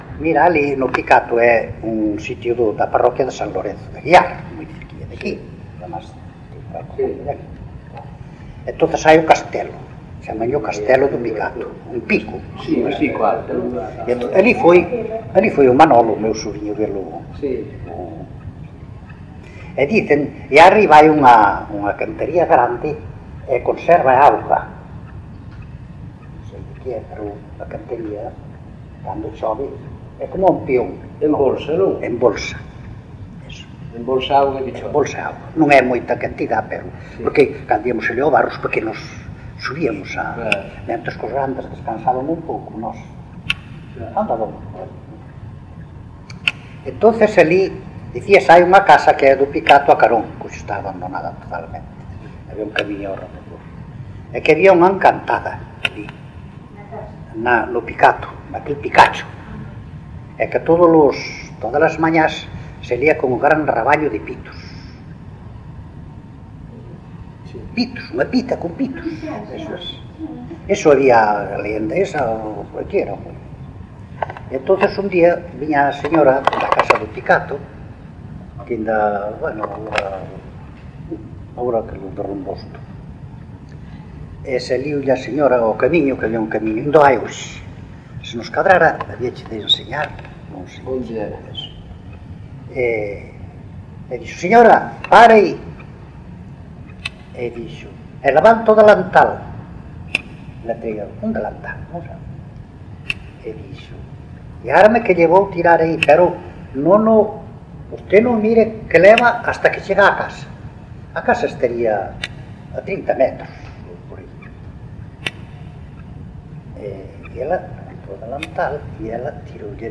Áreas de coñecemento: LITERATURA E DITOS POPULARES > Narrativa > Lendas
Soporte orixinal: Casete